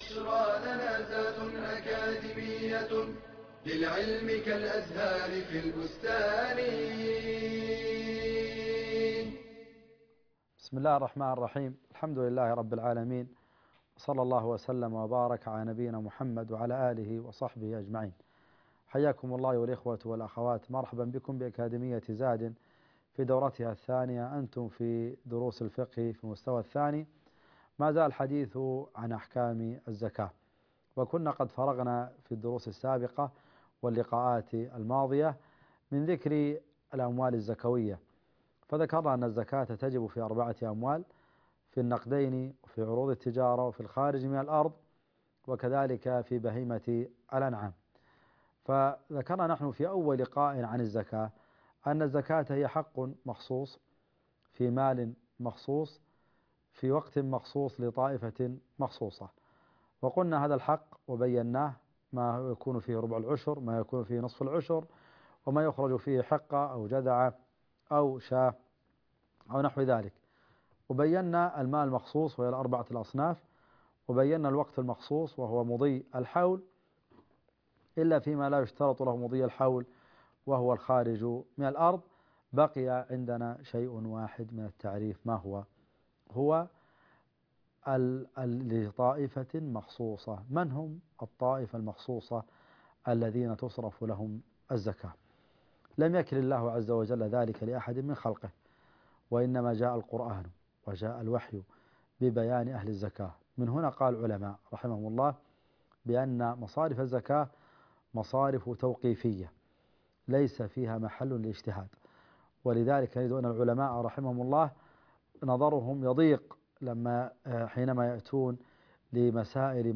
المحاضرة الثالثة والثلاثون لمن نصرف الزكاة؟